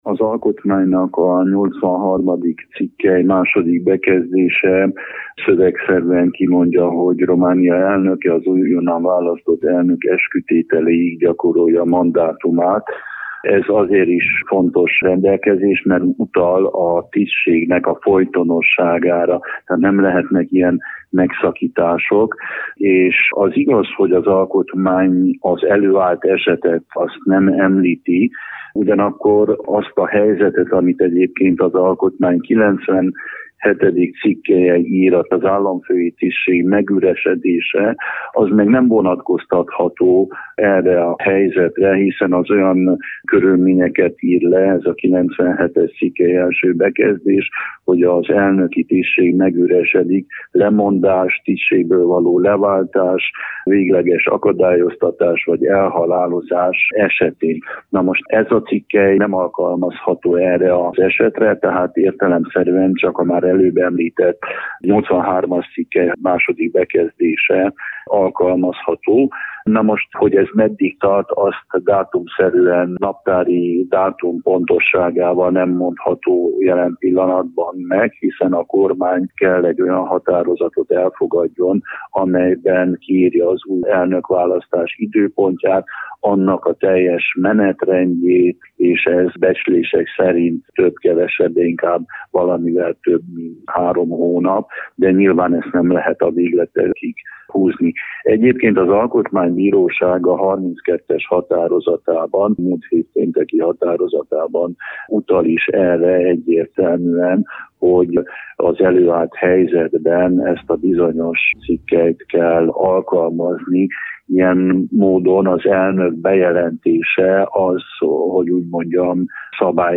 Varga Attila alkotmánybírót, a taláros testület tagját kérdeztük.